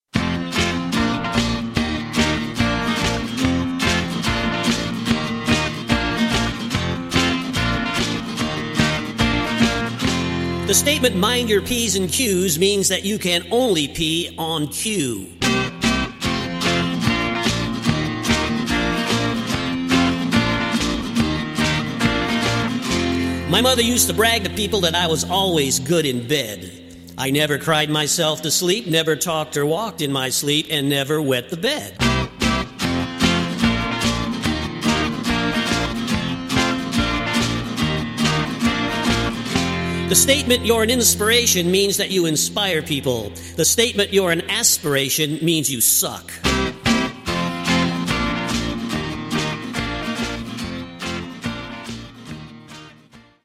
--comedy music